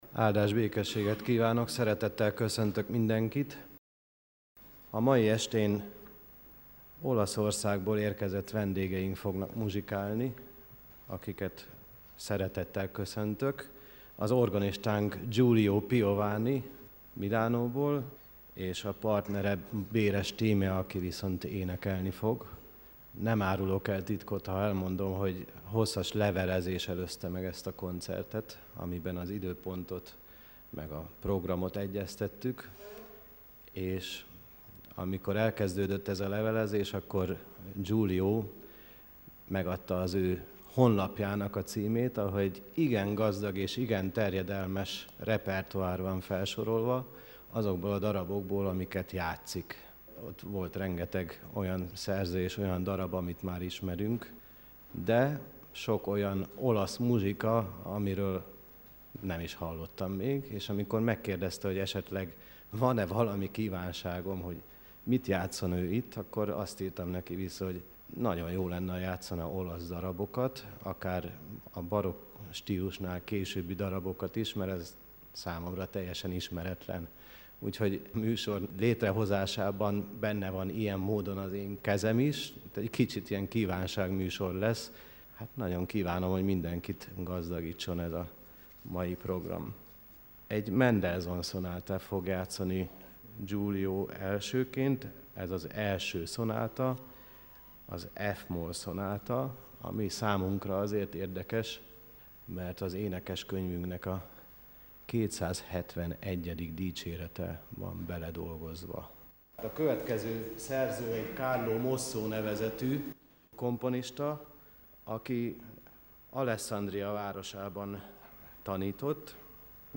2011 április 23, Nagyszombat délután; orgonakoncert